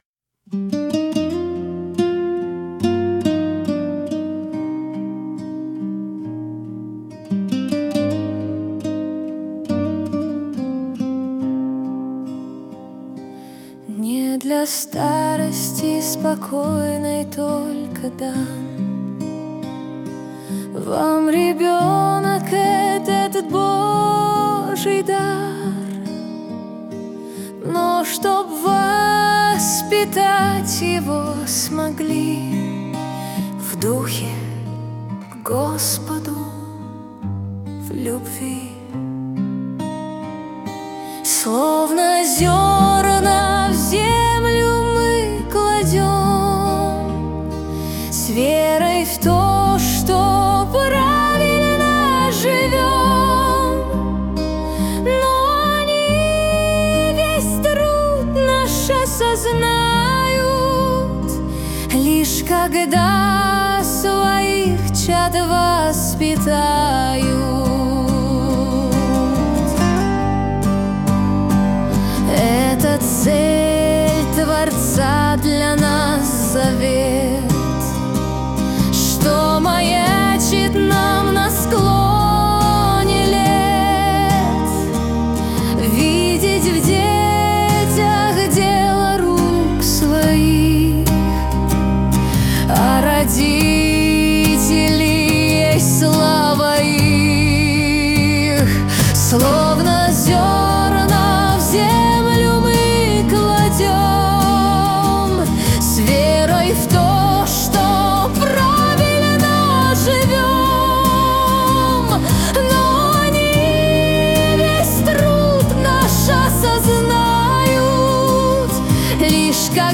песня ai
207 просмотров 724 прослушивания 46 скачиваний BPM: 72